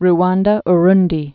(r-ändə--rndē)